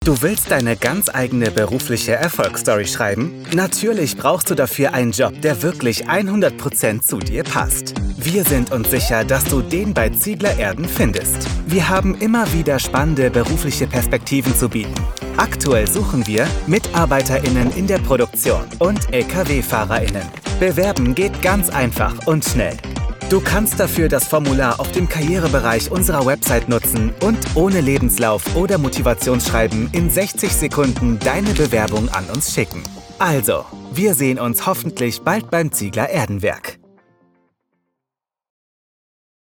Explainer Videos
Soundproof recording booth – 38 dB in the midrange and up to 84 dB in the high frequency range